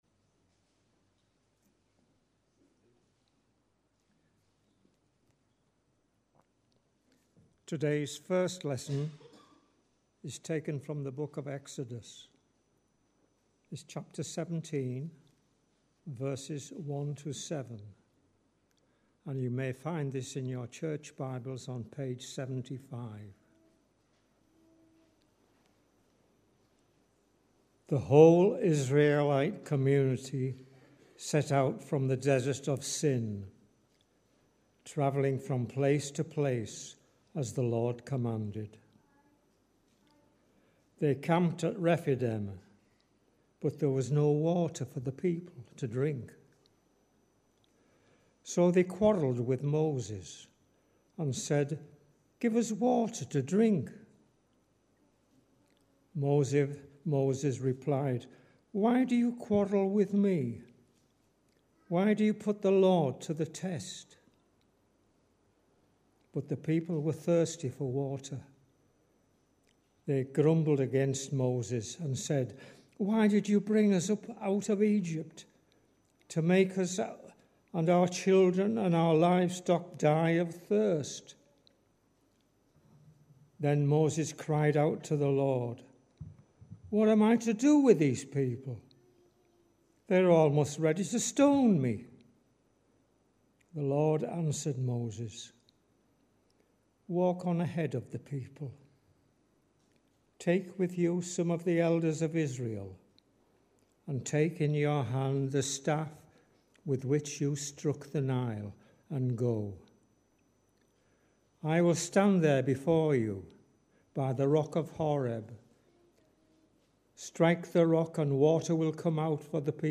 Readings and Sermon 1st October 2023 – Holy Trinity Church Cannes
Readings-Sermon-on-1st-October-2023.mp3